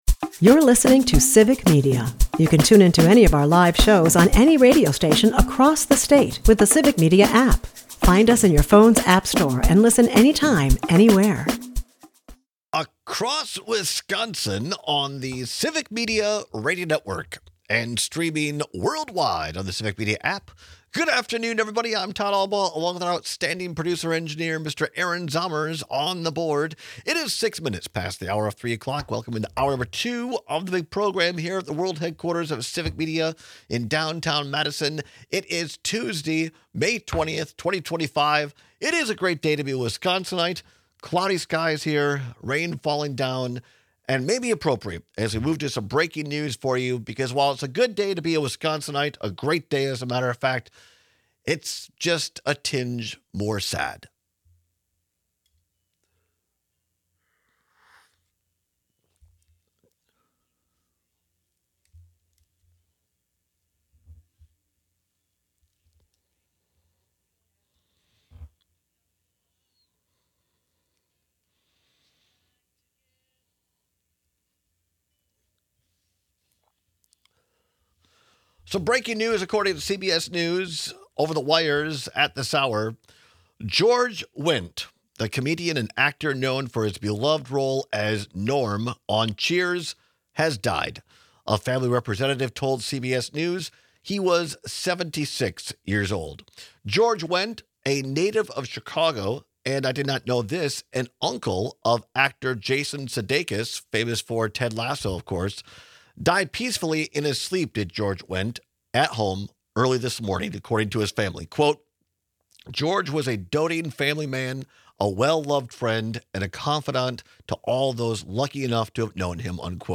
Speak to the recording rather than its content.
We take your calls on whether it’s more annoying to have garbage in your email inbox or your real mailbox.